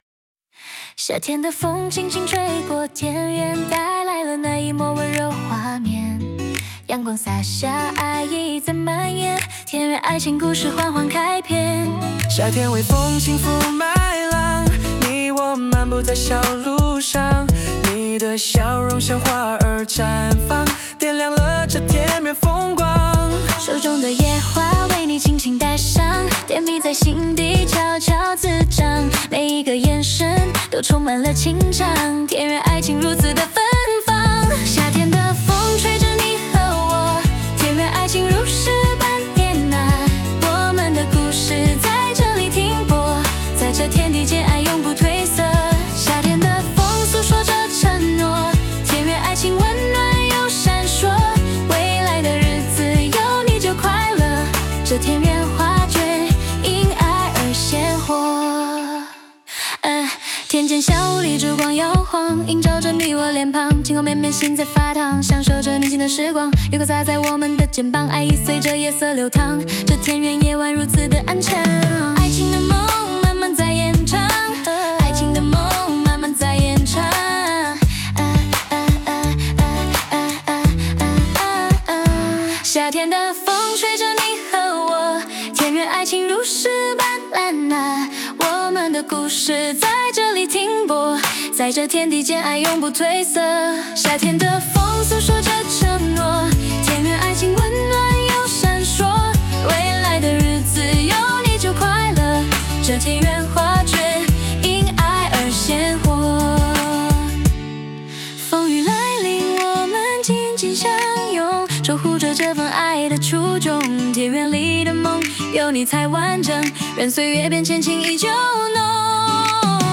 AI生成多首歌曲，并且可根据需要调整歌词等内容